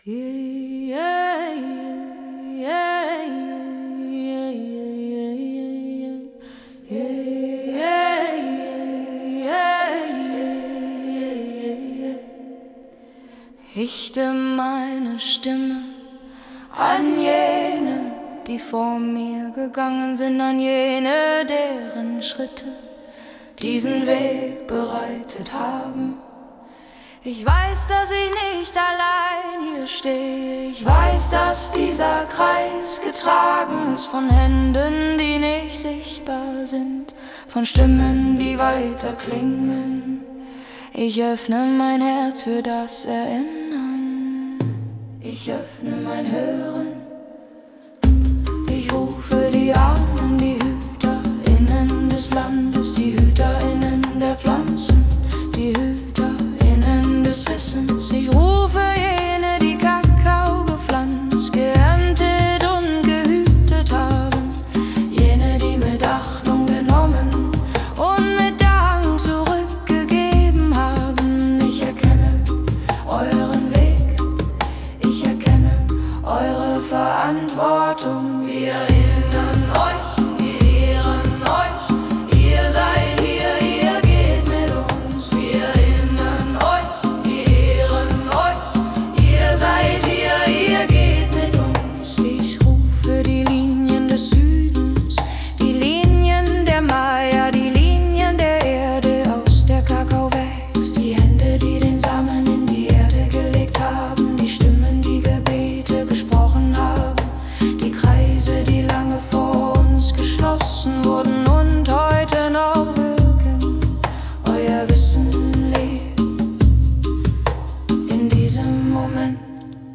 Die Lieder sind lang, ruhig, repetitiv und bewusst schlicht.
• klare Sprache, langsamer Puls